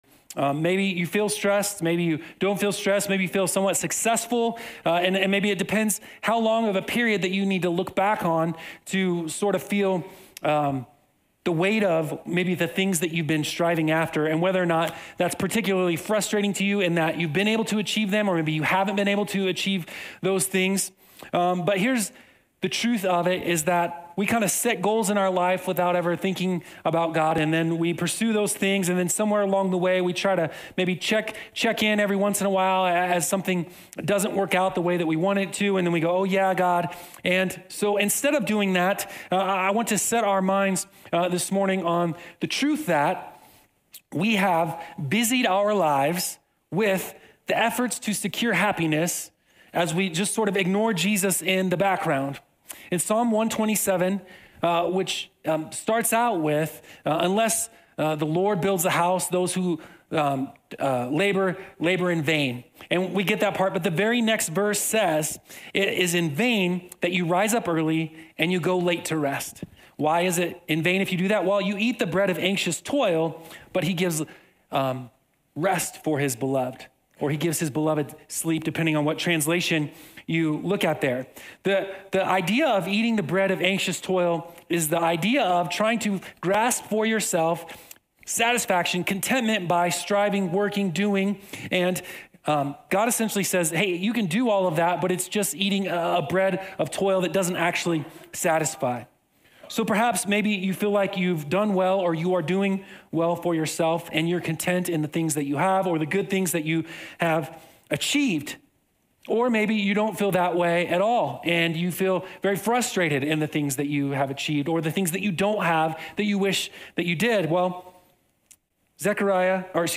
Sermons - LUMA BIBLE CHURCH